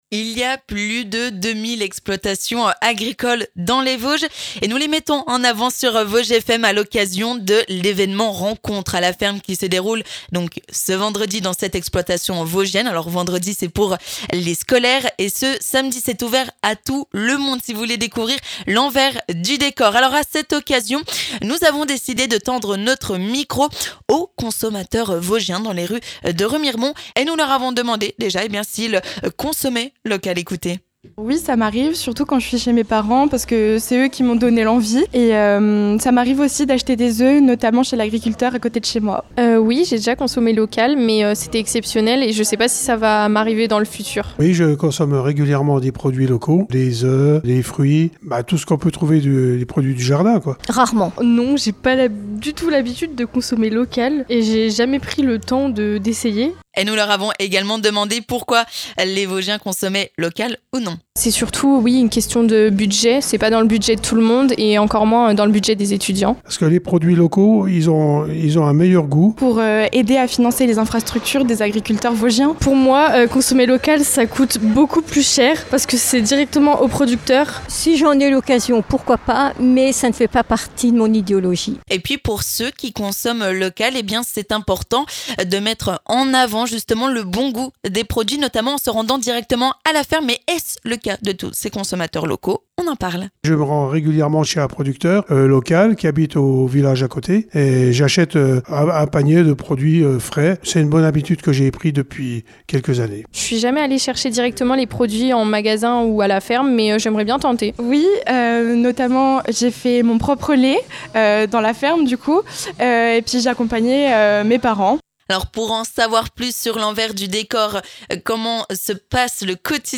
A cette occasion, nous sommes allés à la rencontre des consommateurs vosgiens pour savoir s'ils consommaient local !